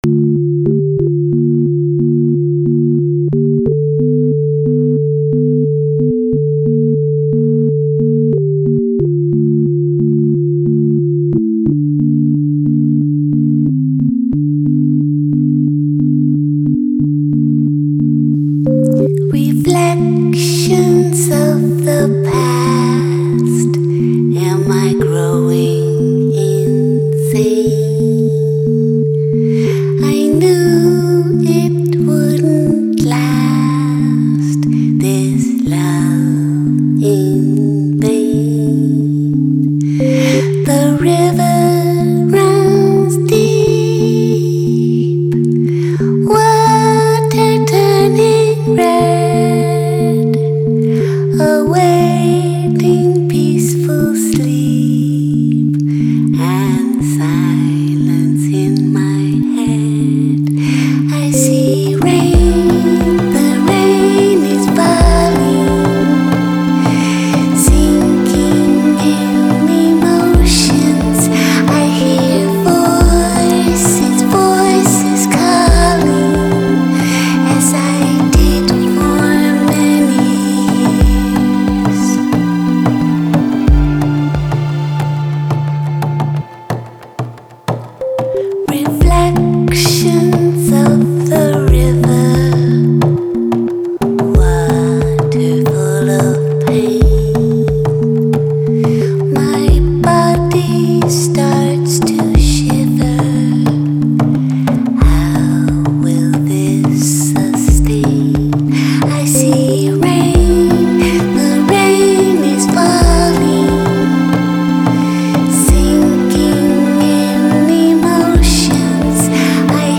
Genre: Deep House, Downtempo.